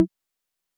S 78_Tom2.wav